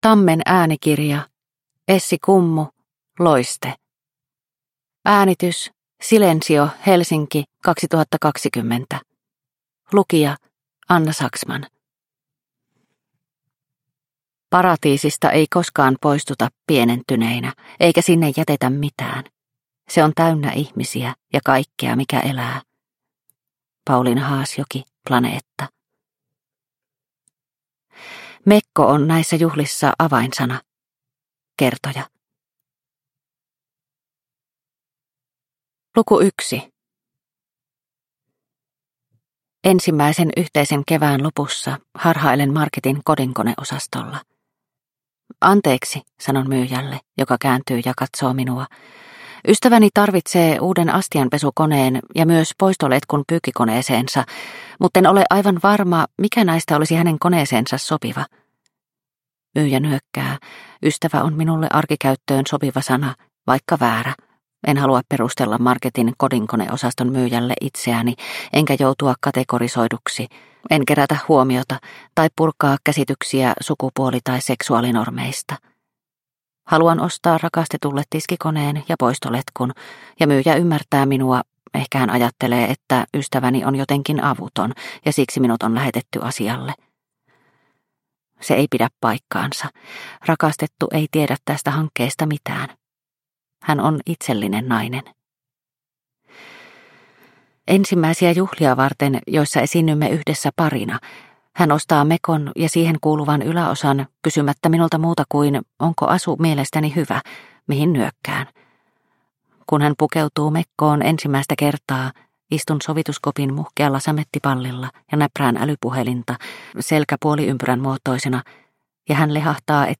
Loiste – Ljudbok – Laddas ner